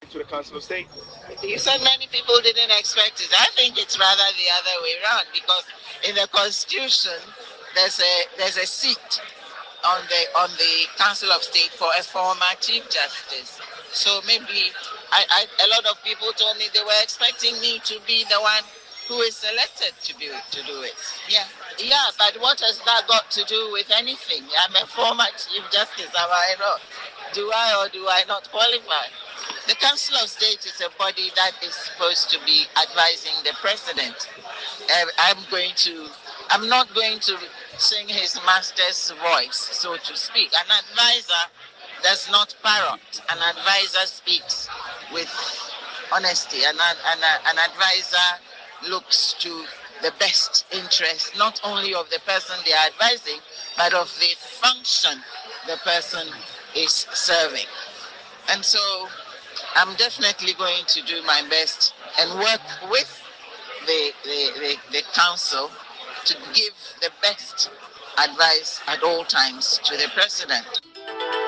Speaking after the swearing-in ceremony in Accra on Tuesday, February 18, Justice Akuffo made it clear that her advisory role would be based on honesty and principle, rather than blind loyalty.